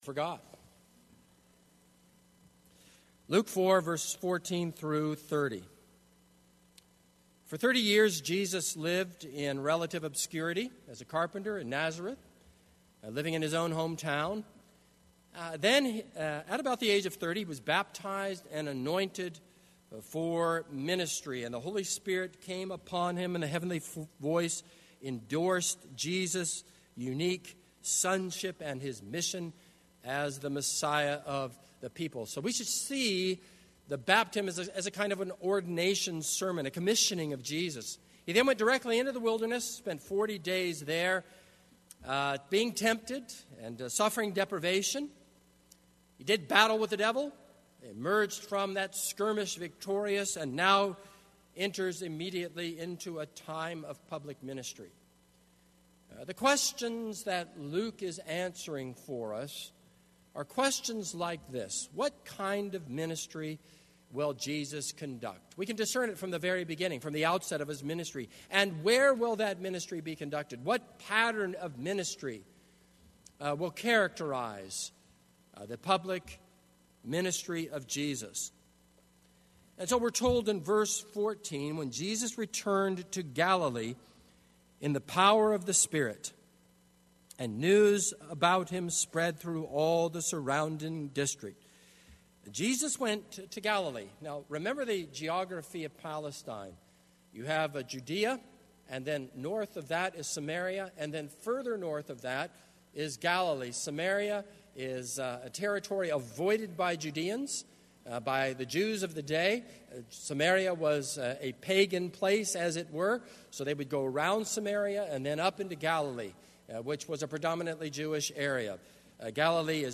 This is a sermon on Luke 4:14-30.